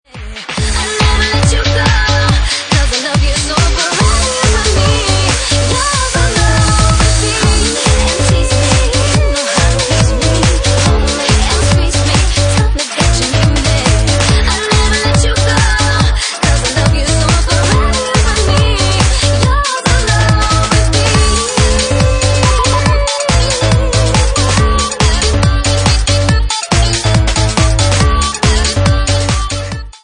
Bassline House at 142 bpm